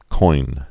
(coin)